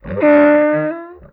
MONSTER_Squeel_Deep_mono.wav